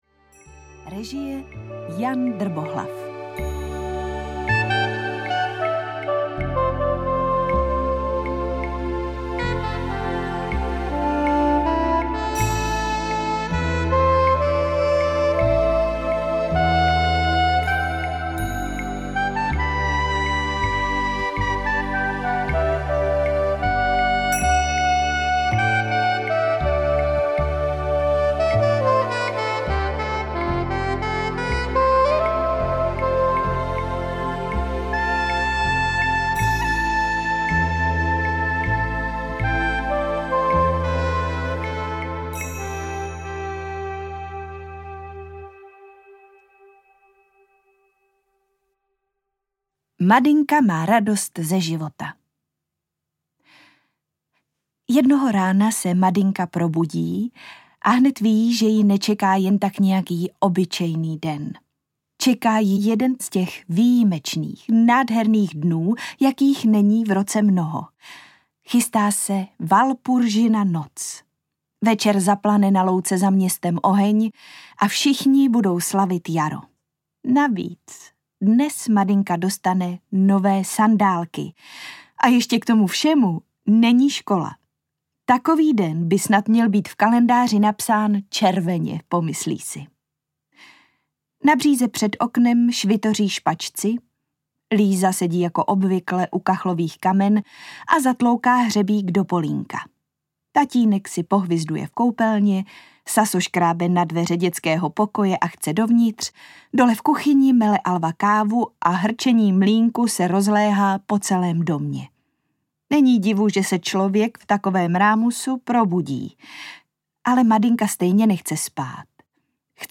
Madynka zachránkyně audiokniha
Ukázka z knihy